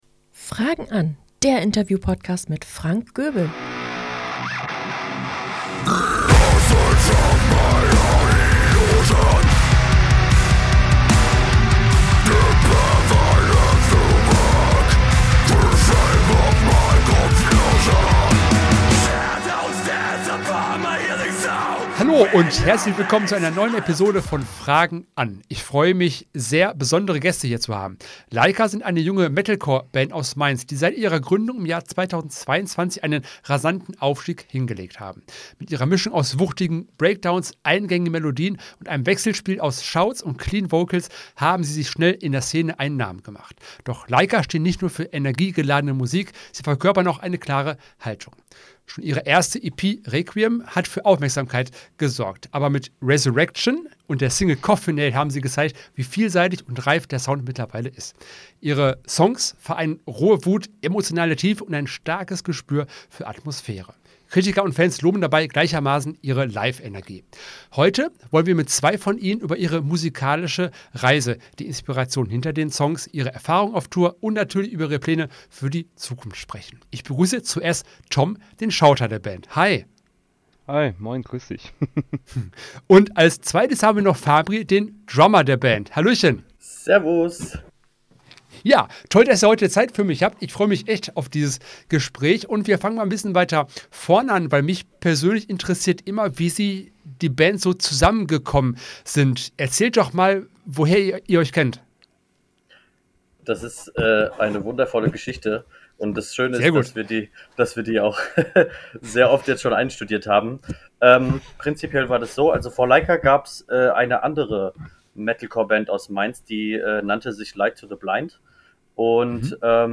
Ob kleine Clubshows oder Festivalbühne – die Jungs erzählen offen und ehrlich, was sie antreibt, welche Gänsehaut-Momente sie nie vergessen werden und was für die Zukunft noch geplant ist. Eine Folge voller Energie, Leidenschaft und echter Einblicke ins Musikerleben – perfekt für alle, die Metalcore lieben oder einfach wissen wollen, wie es hinter den Kulissen einer Band aussieht!